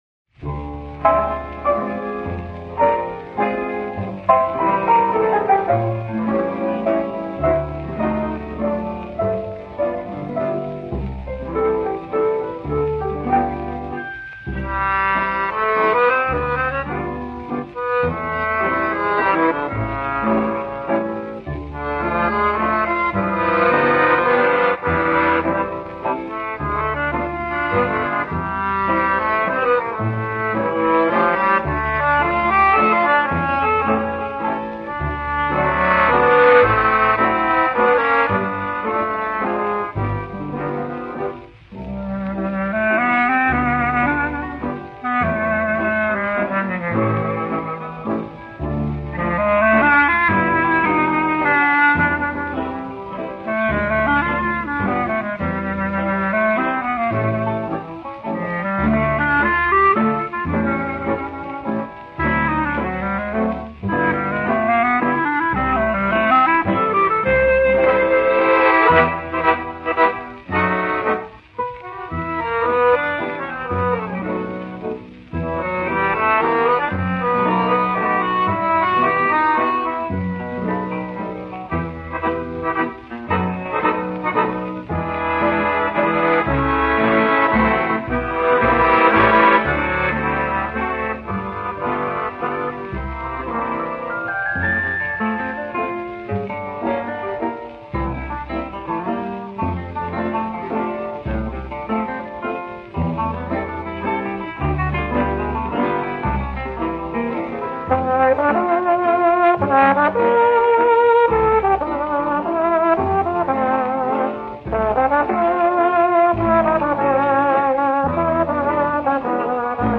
Красивый, плавный вальс.
Медленный вальс